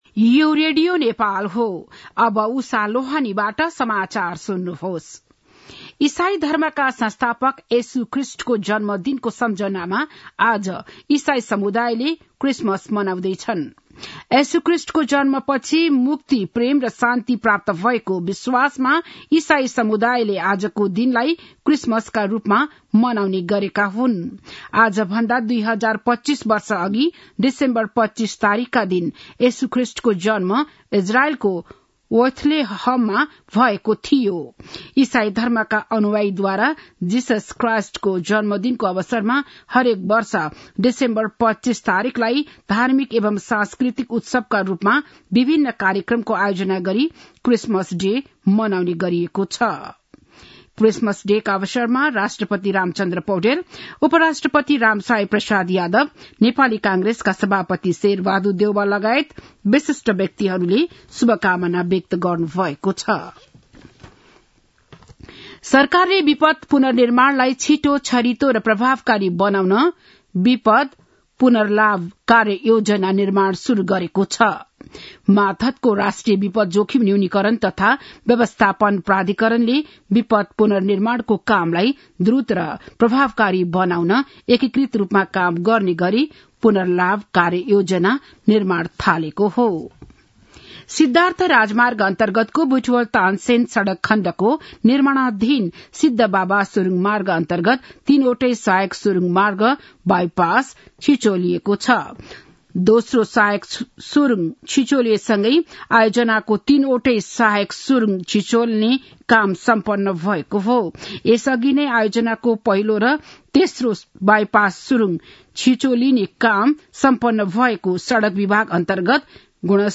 बिहान ११ बजेको नेपाली समाचार : ११ पुष , २०८१
11-am-nepali-news-1-20.mp3